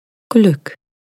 Royalty free voices